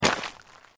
sporecarrier_foot_l03.mp3